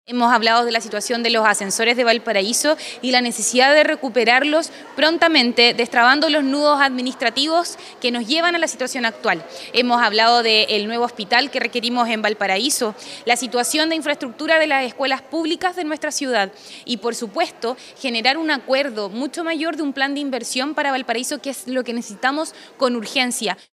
En relación a esta reunión, la alcaldesa de la comuna puerto declaró que con el mandatario conversaron sobre el estado de los ascensores en Valparaíso, un nuevo hospital para la comuna, la infraestructura de las escuelas públicas y la generación de un acuerdo para un plan de inversión.